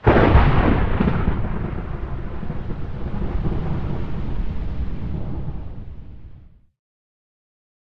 thunder3.ogg